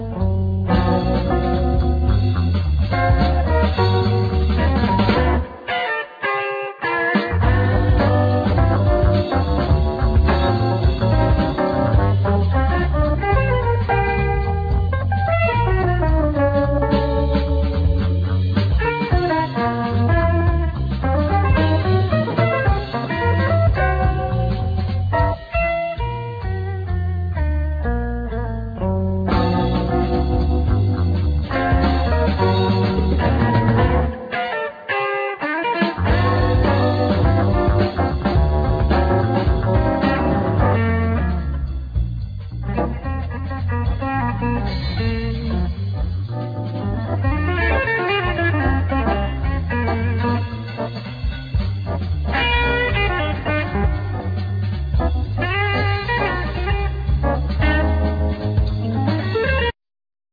Keyboards, Fender piano
Sopranosax, Violin
Bass
Percussions
Drums
Vocal
Acoustic guitar
Guitar